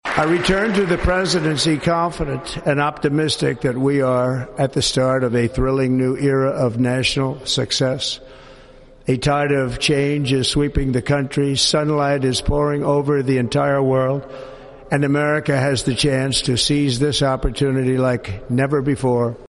The inauguration was held indoors at the Capitol Rotunda, a decision made in response to dangerously low temperatures, making it the first indoor inauguration in 40 years.